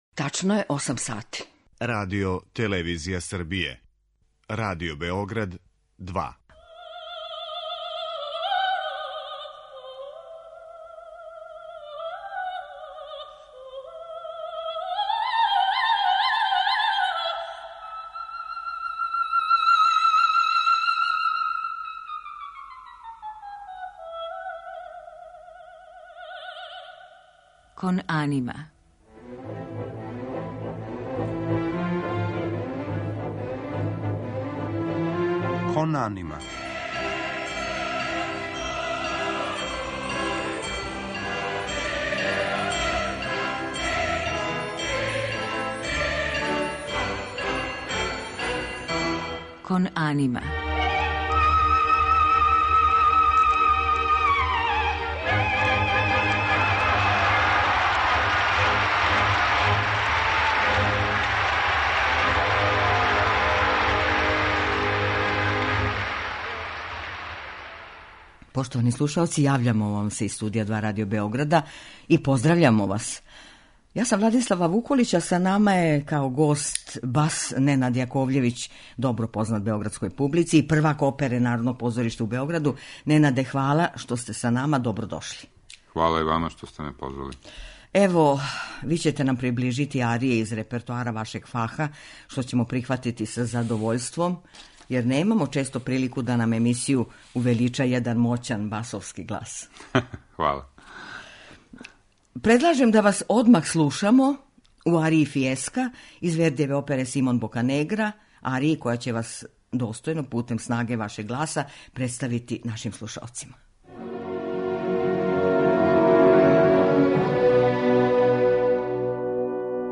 У музичком делу биће емитоване арије из већ поменутих опера, у његовом извођењу.